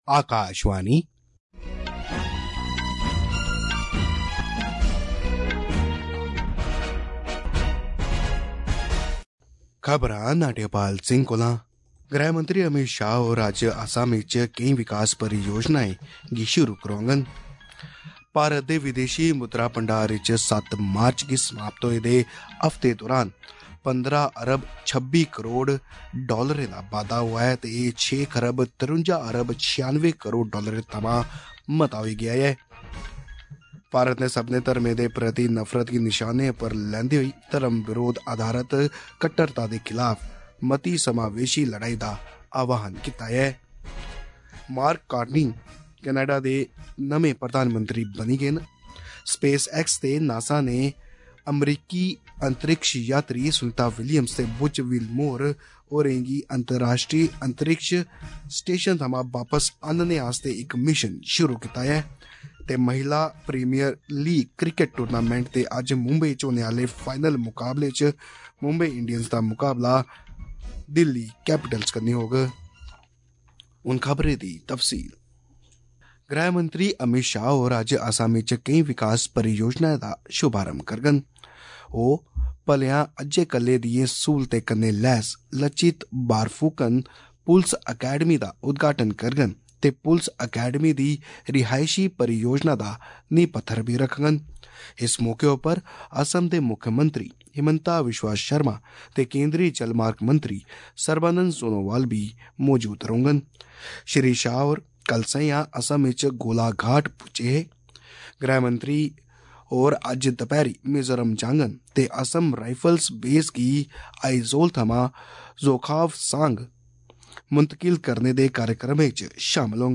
AUDIO-OF-MORNING-DOGRI-NEWS-BULLETIN-NSD-9.mp3